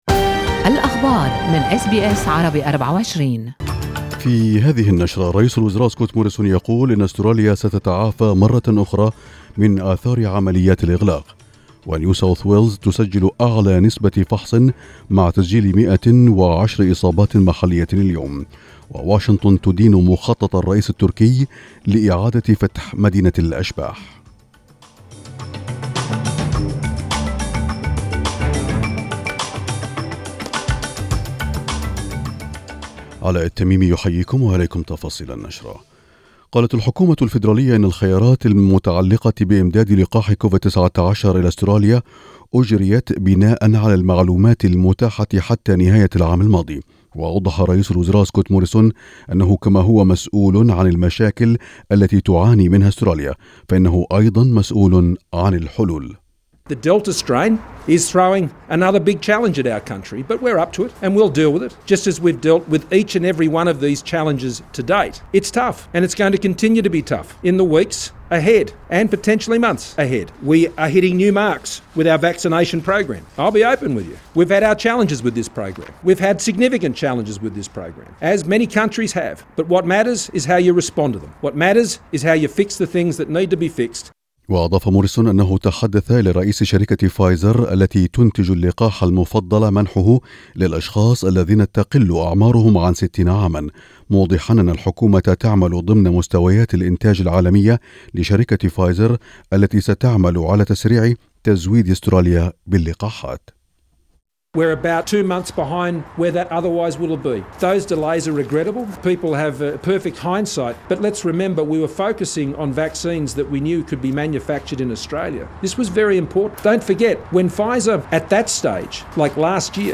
نشرة أخبار المساء s21/7/2021